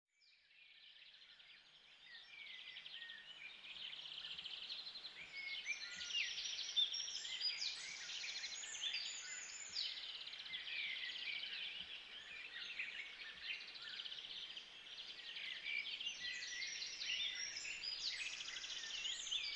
rainforest.wav